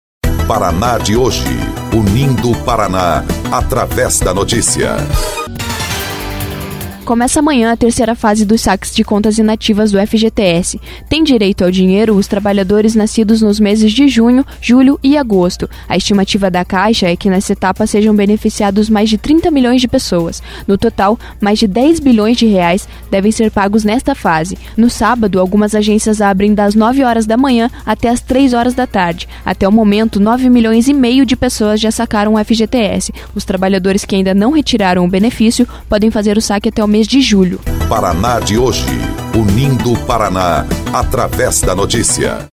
11.05 – BOLETIM – Nova fase para saque do FGTS começa nesta sexta-feira